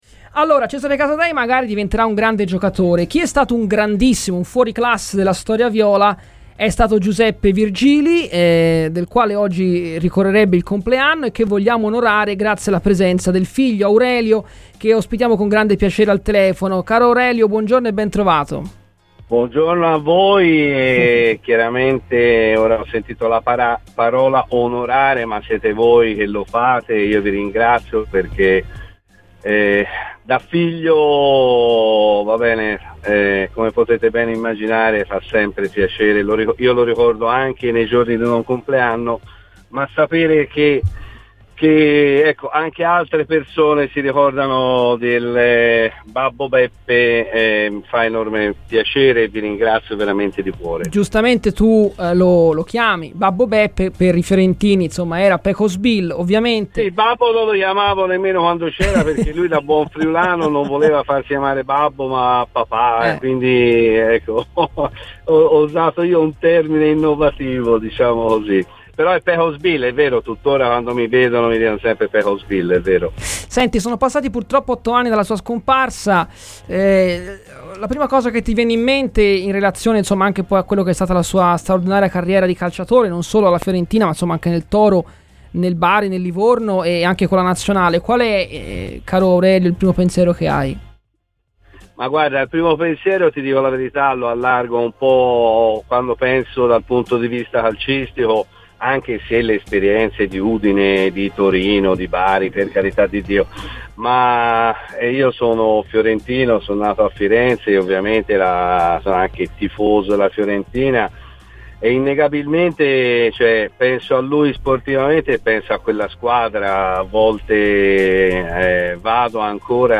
Poi ci sono anche altre questioni di carattere economico" ASCOLTA IL PODCAST PER L'INTERVENTO COMPLETO